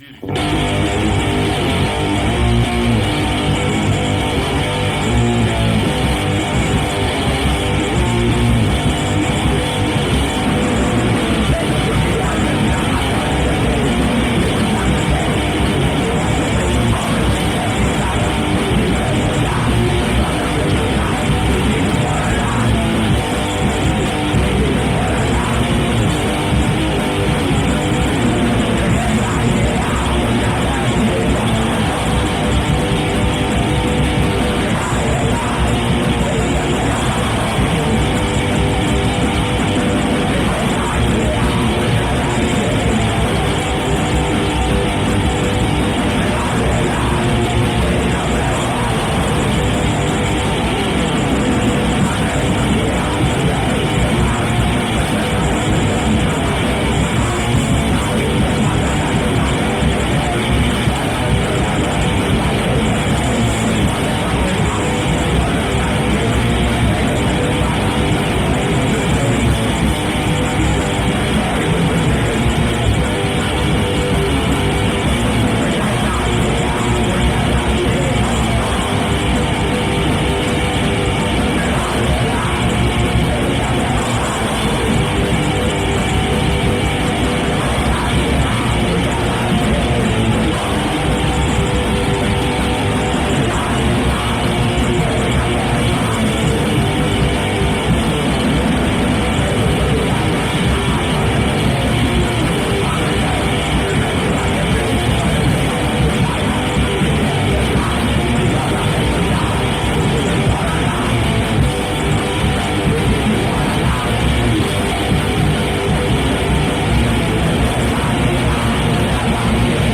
unfortunately low quality.